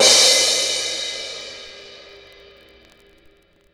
Dusty Cymbal 01.wav